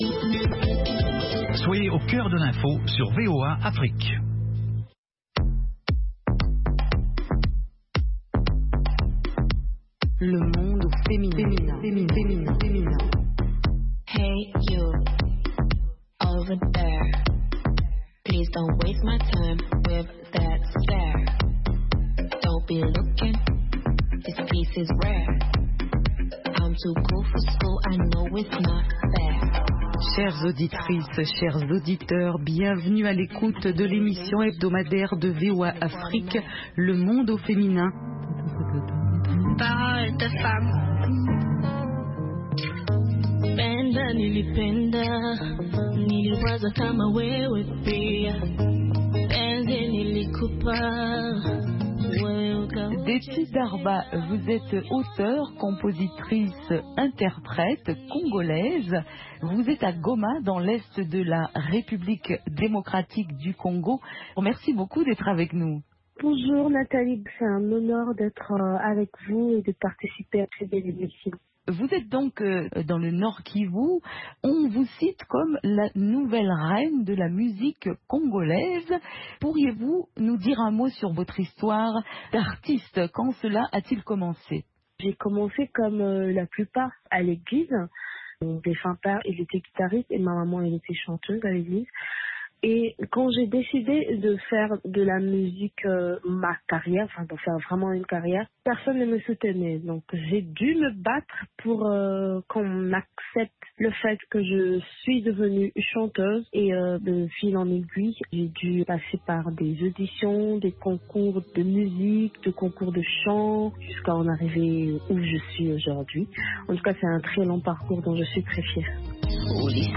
Le Monde au Féminin: entretien musical